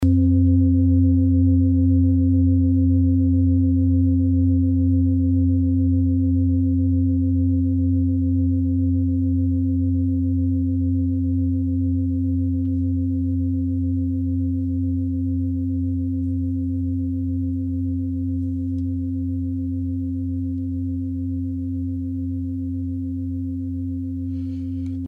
Klangschalen-Typ: Bengalen und Tibet
Klangschale 1 im Set 4
Durchmesser = 30,9cm
(Aufgenommen mit dem Filzklöppel/Gummischlegel)
klangschale-set-4-1.mp3